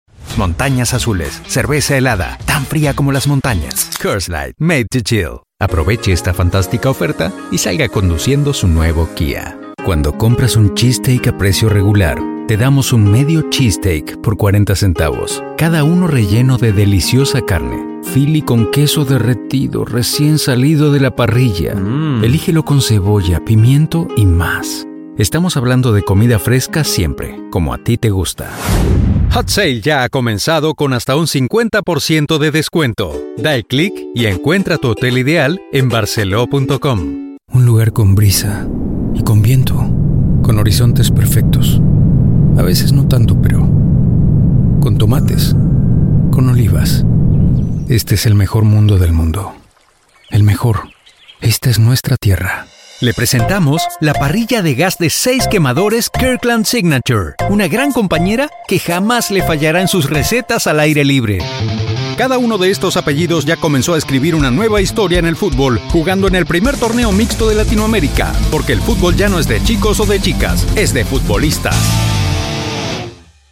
Commercial Demo
Professional Home Studio; Isolated floor and acoustic treatment.
Baritone
WarmConversationalExperiencedReliableFriendly